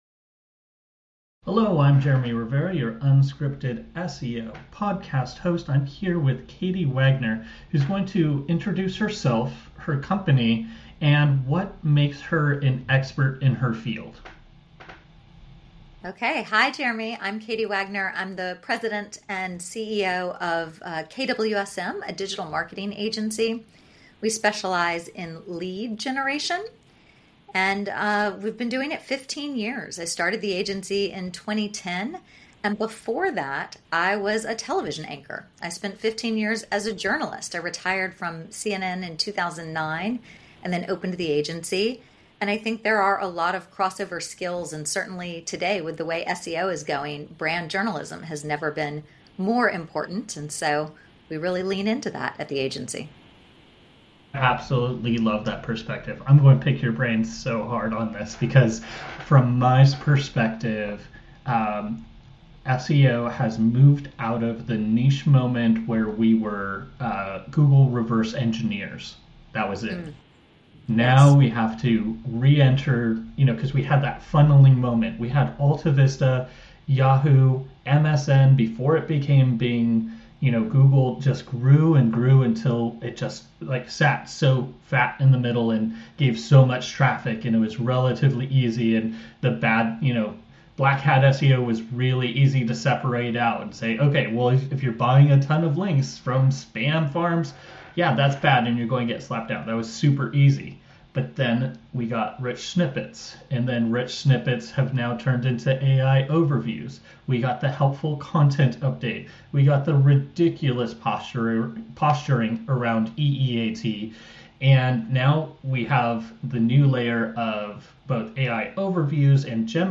SEO & Brand Journalism: A Conversation